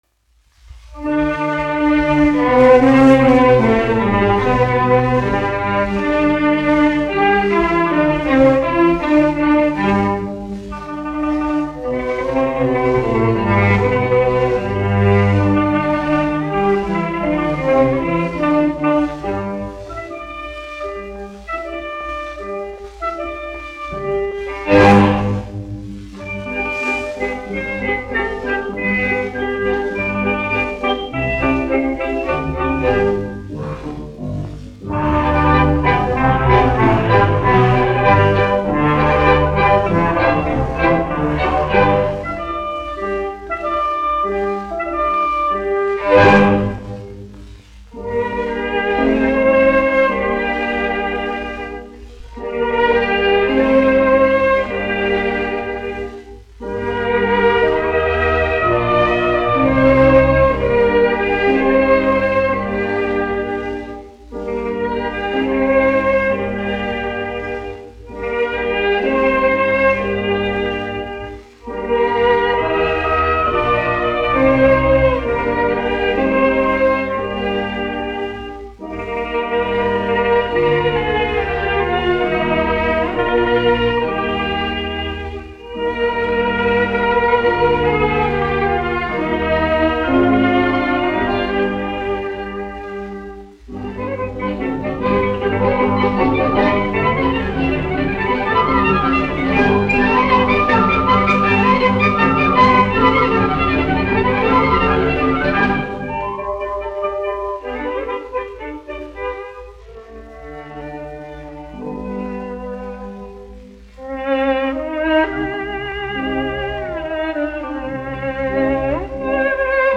Alexander Schirmann, aranžētājs
1 skpl. : analogs, 78 apgr/min, mono ; 25 cm
Tautasdziesmas, krievu--Instrumentāli pārlikumi
Orķestra mūzika
Skaņuplate